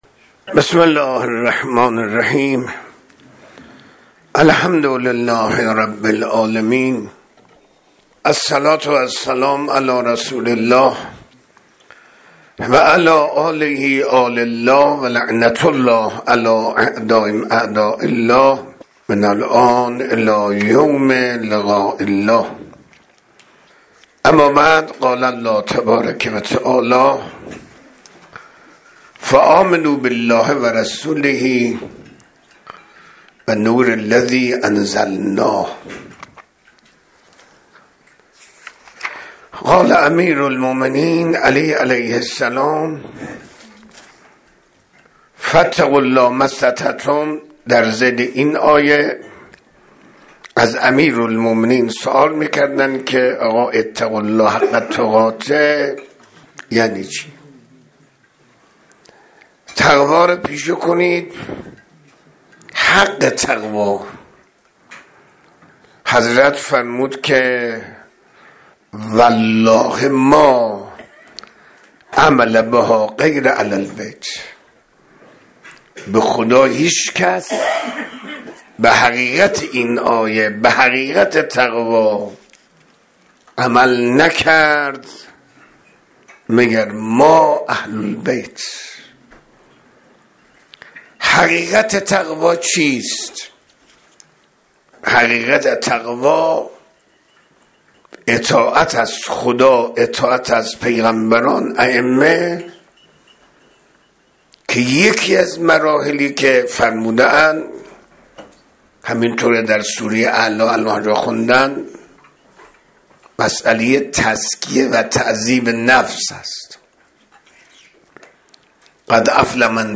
سخنرانی های صوتی
منبر